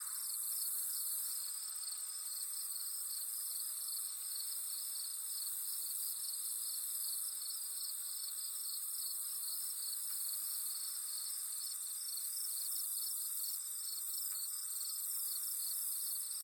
night.ogg